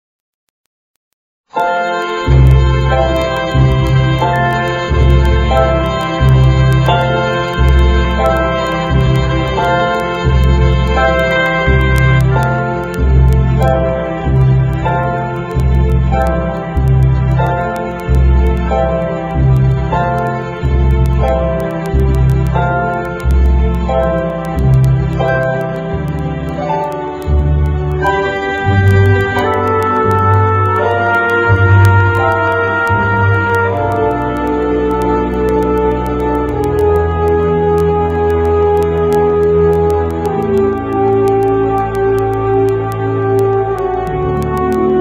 NOTE: Background Tracks 11 Thru 22